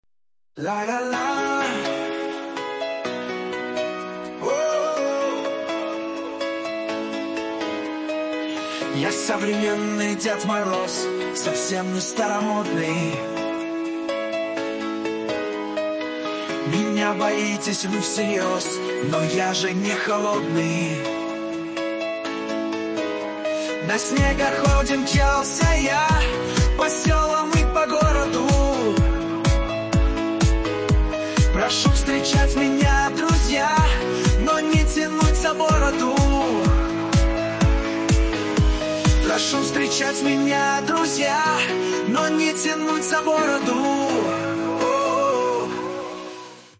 Фрагмент исполнения 4 вариант: